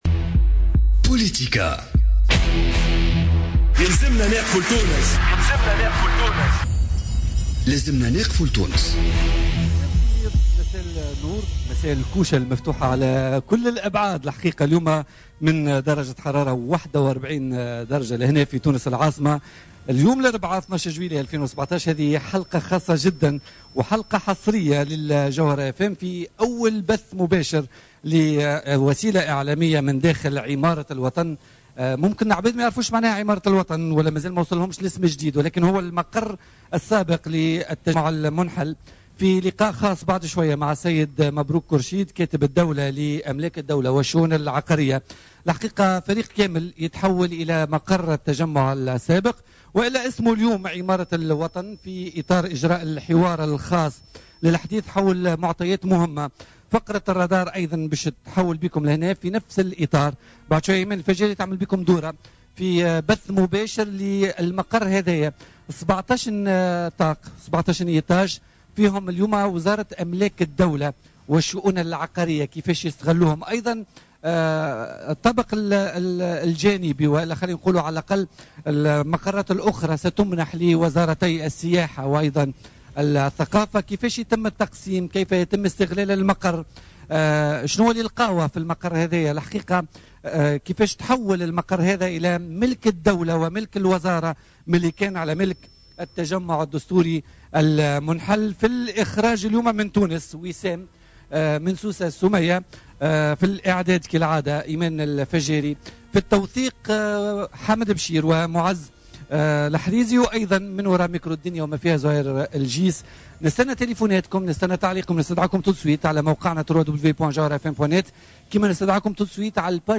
مبروك كرشيد ضيف بوليتيكا من مقر التجمع سابقا عمارة الوطن حاليا